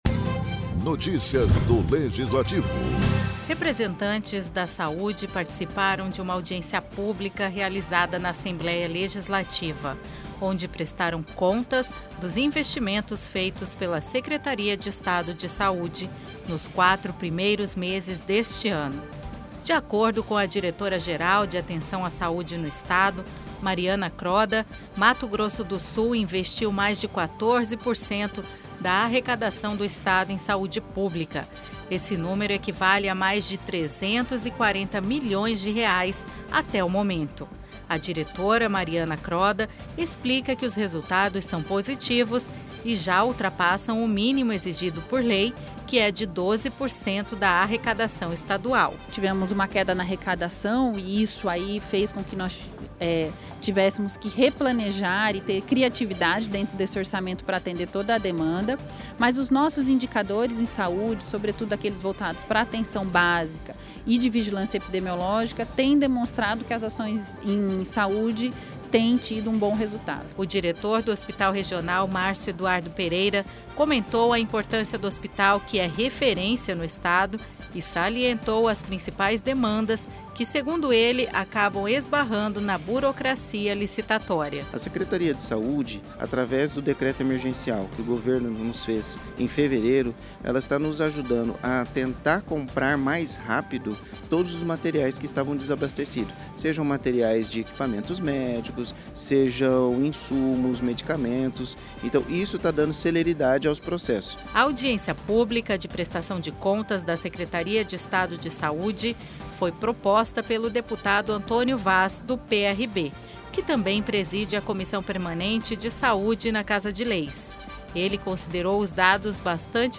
Download Locução